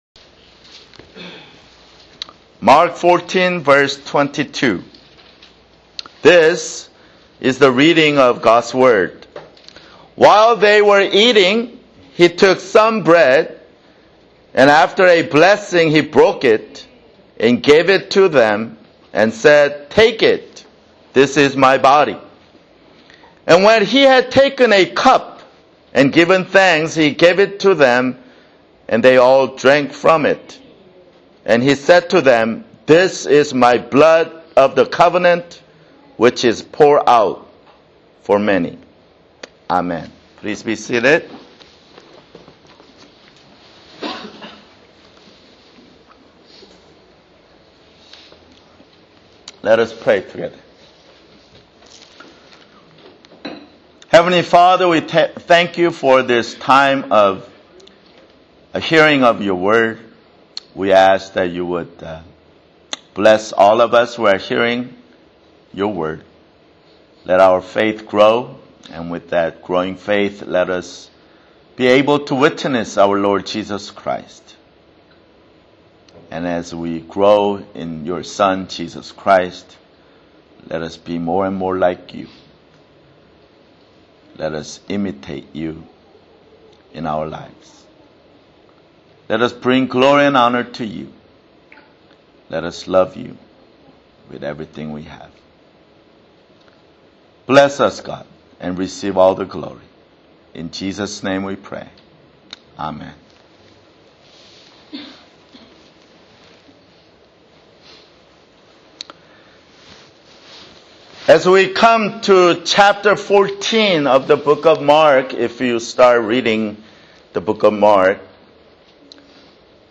[Sermon] Mark 14:22-24